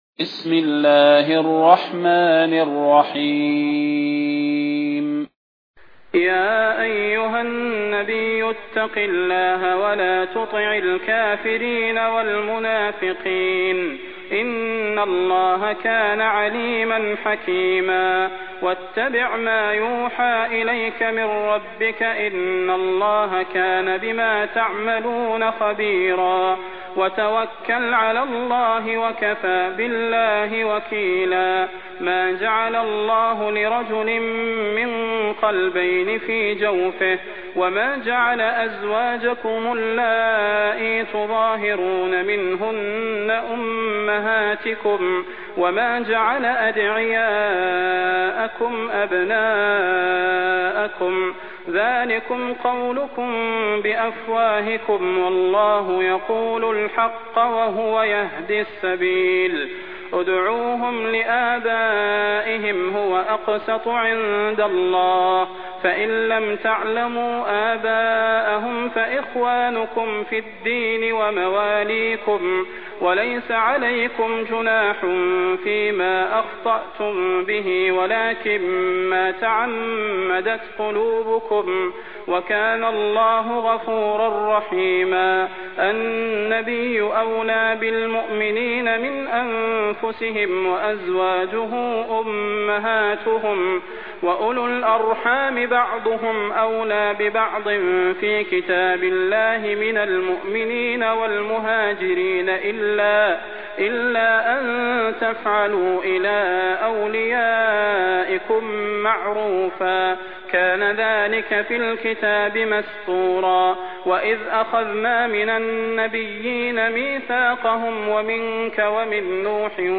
المكان: المسجد النبوي الشيخ: فضيلة الشيخ د. صلاح بن محمد البدير فضيلة الشيخ د. صلاح بن محمد البدير الأحزاب